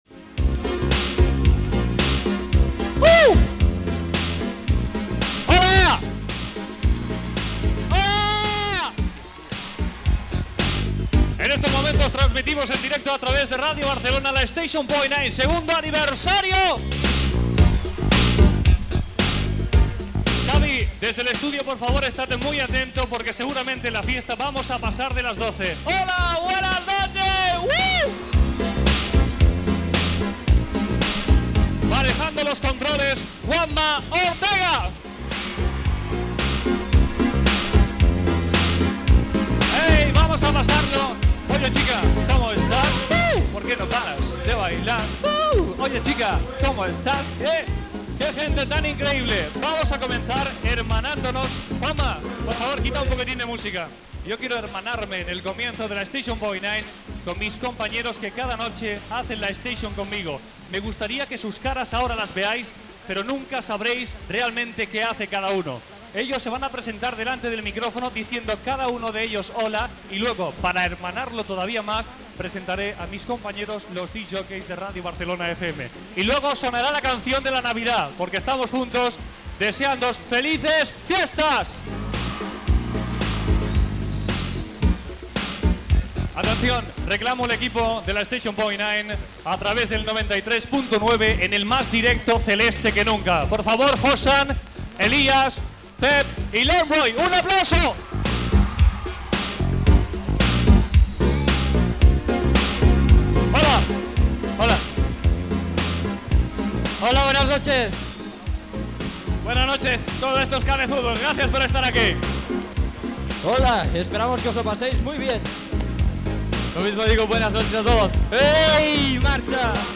Programa segon anniversari de Station Point Nine fet en directe a la sala Zeleste de Barcelona.
Musical